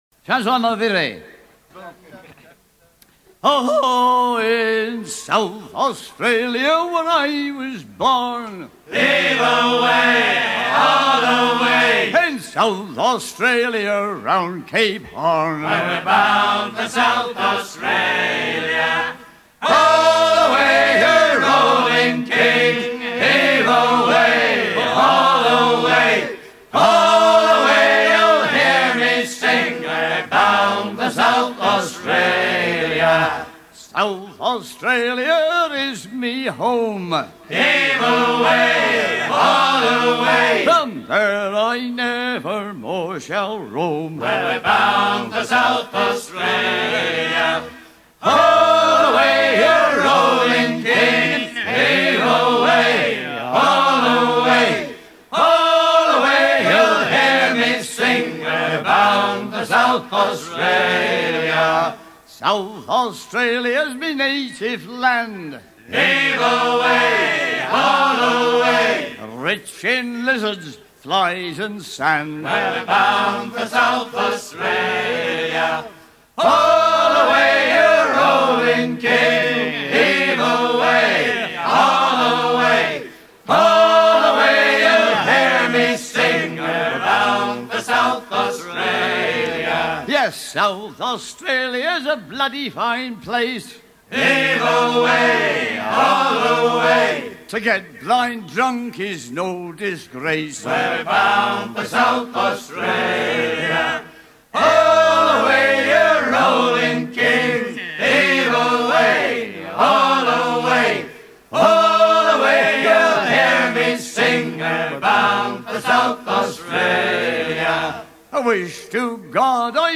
chant en usage sur les voiliers faisant le commerce de la laine et sur ceux allant dans les colonies
Pièce musicale éditée